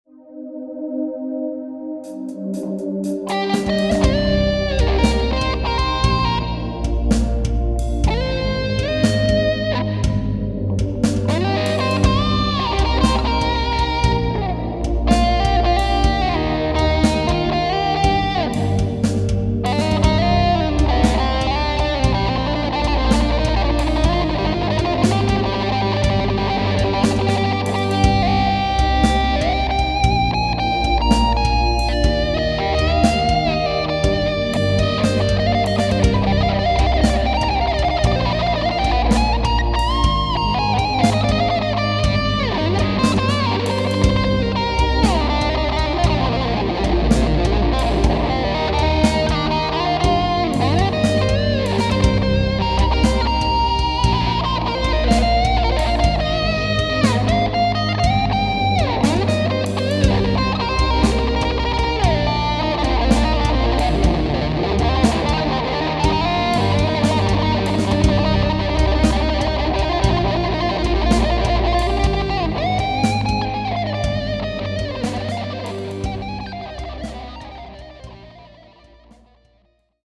Distortion E-Gitarre mit Sequenzer- und Midibegleitung.